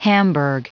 Prononciation du mot hamburg en anglais (fichier audio)
Prononciation du mot : hamburg